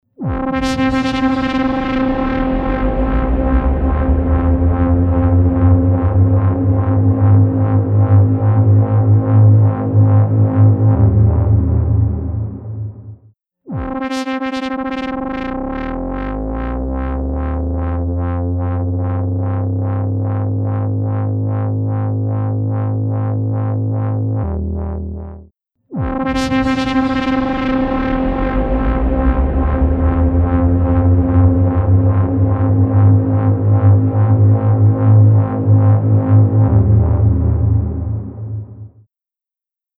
Massive Otherworldly Reverb
Blackhole | Synth Bass | Preset: Dark Decay
Blackhole-Eventide-Sound-Design-Synth-Bass-Dark-Decay.mp3